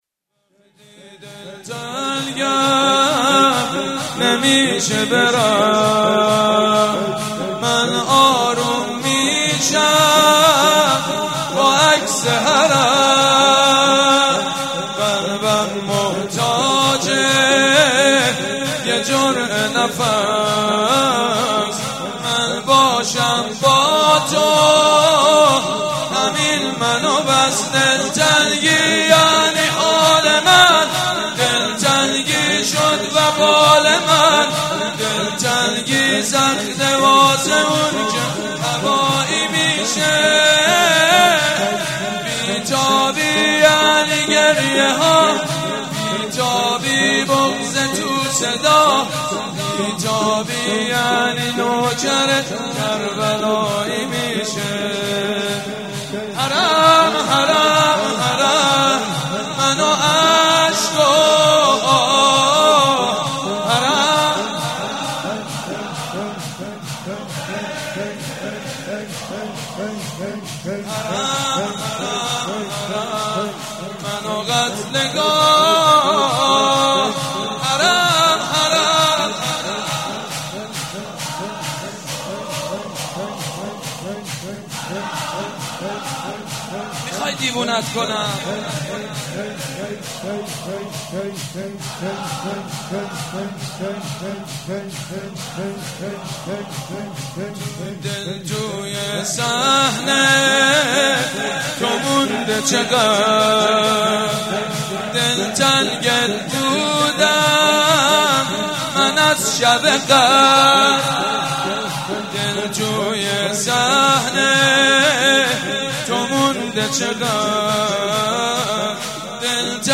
شب سوم محرم الحرام 1394 | هیات ریحانه الحسین | حاج سید مجید بنی فاطمه
وقتی دلتنگم نمیشه برم | شور | حضرت امام حسین علیه السلام